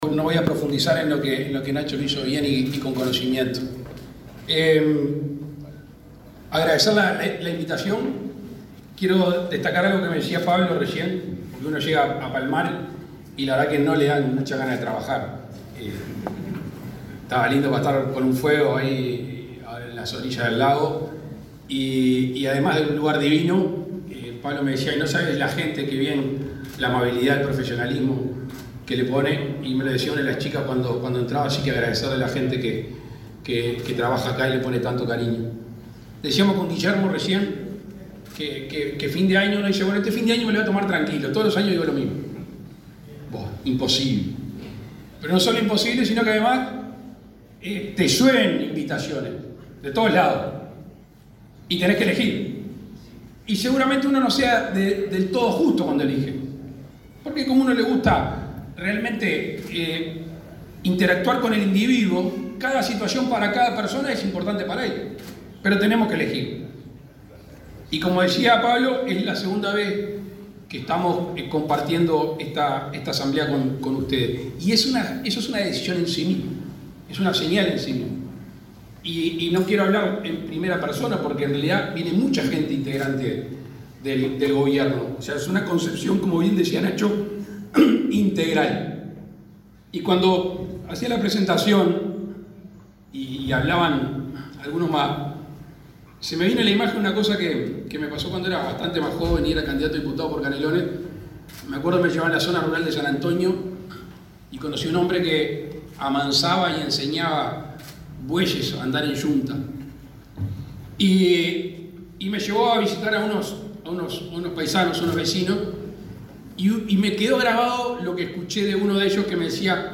El presidente de la República, Luis Lacalle Pou, se expresó, este martes 12 en Soriano, en la apertura del encuentro de Cooperativas Agrarias